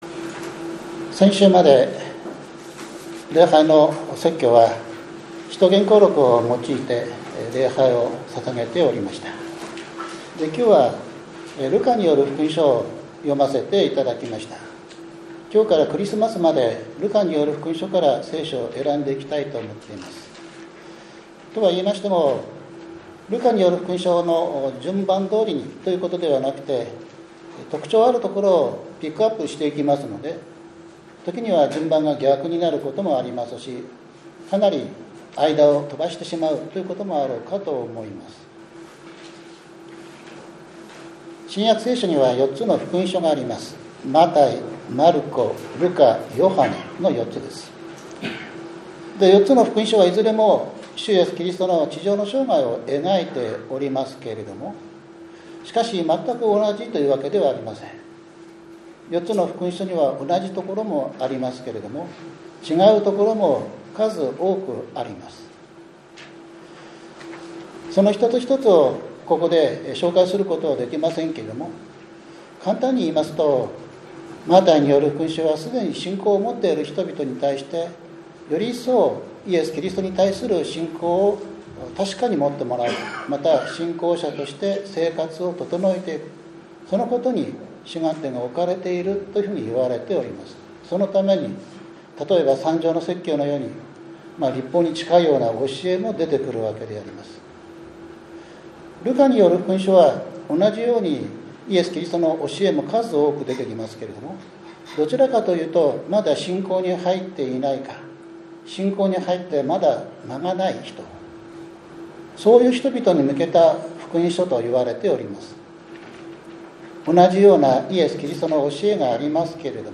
６月１５日（日）主日礼拝 イザヤ書４２章５節～７節 ルカによる福音書４章１６節～２１節